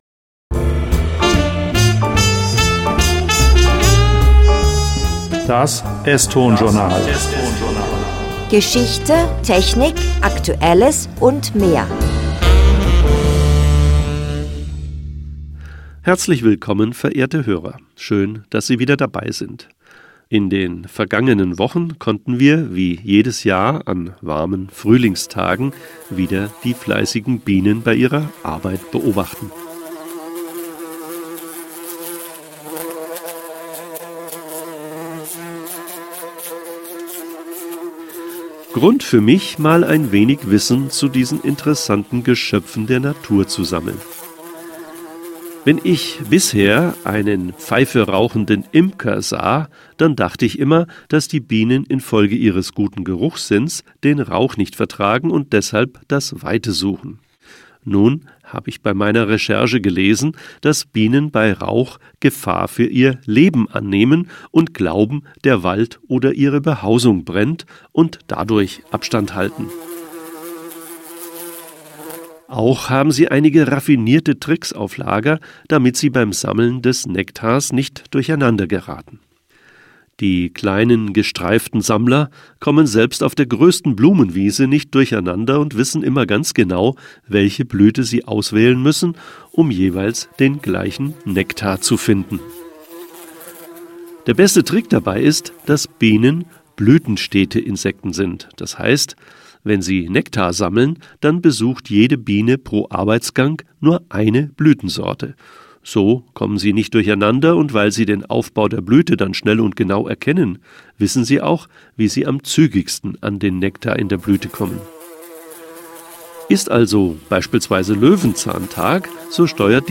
Autor und Sprecher: